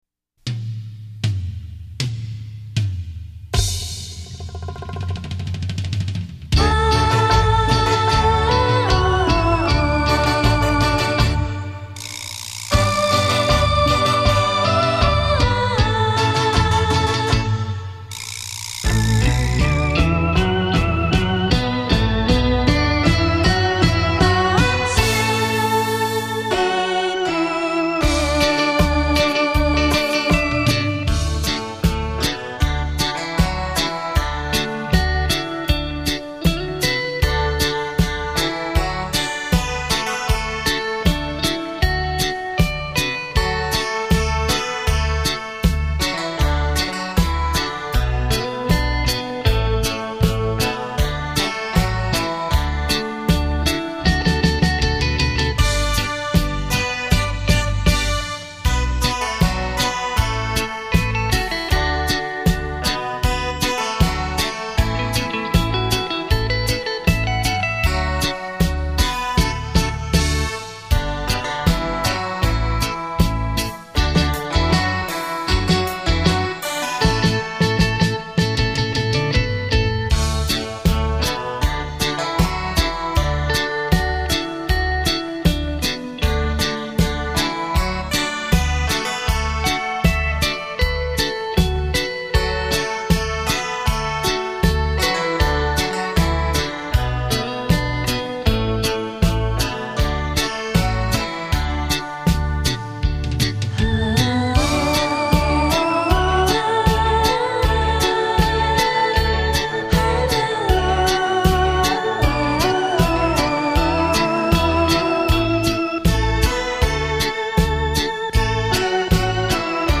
这个版本声音浑厚，音质铿锵有力，是套不可多得的收藏佳品